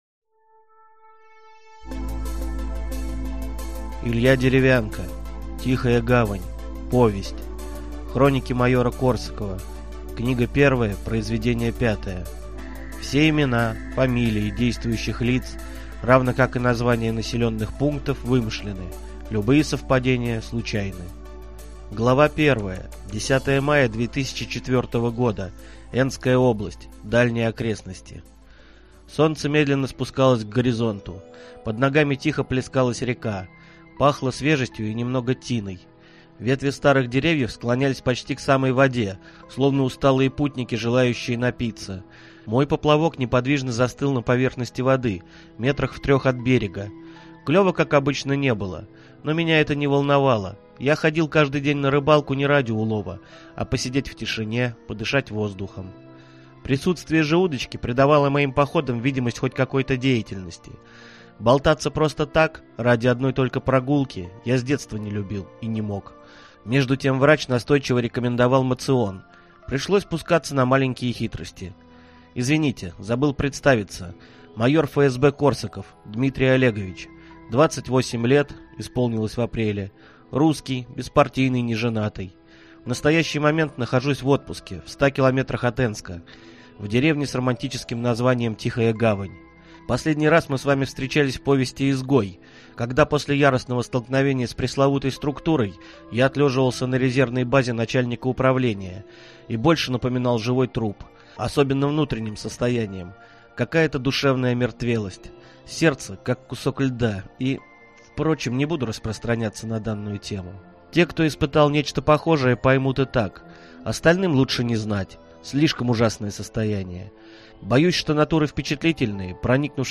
Аудиокнига Тихая гавань | Библиотека аудиокниг